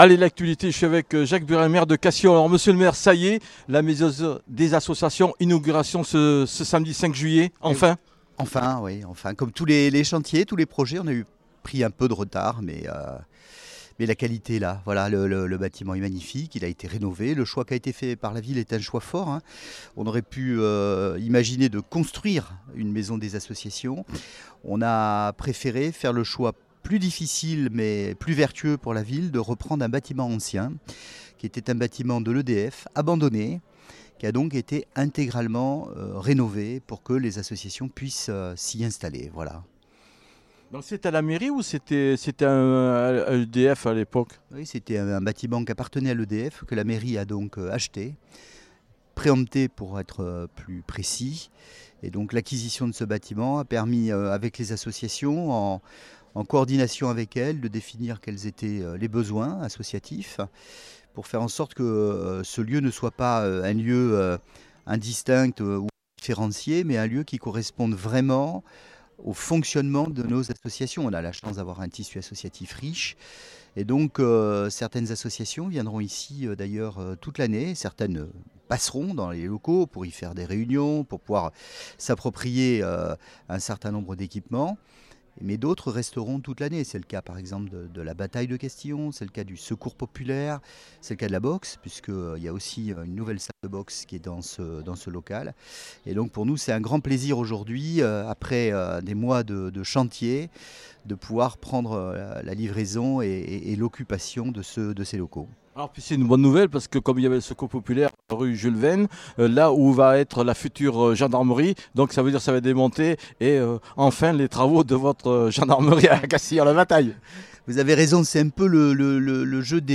Le maire Jacques Breillat a inauguré ce samedi matin la Maison des Associations, désormais opérationnelle depuis plusieurs semaines,cette inauguration marque l’achèvement officiel de la phase 1 du projet, en présence de l’ensemble des partenaires et parties prenantes.